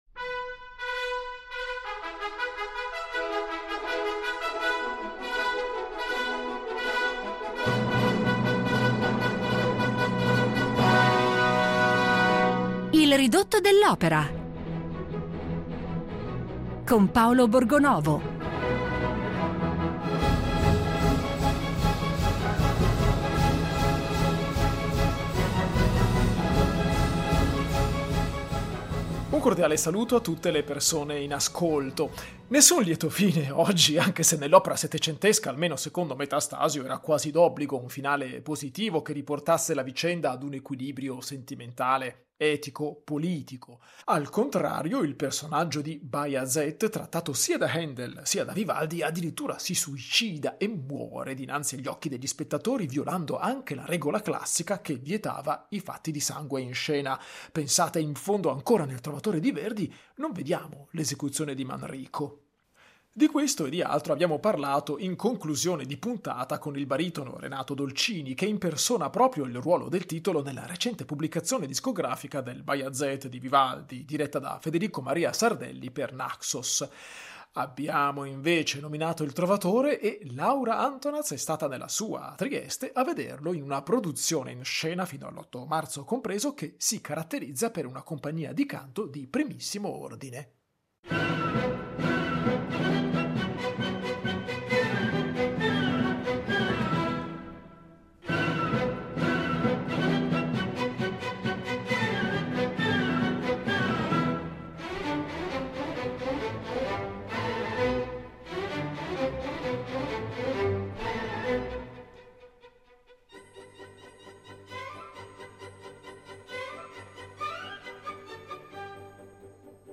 Ridotto dell'opera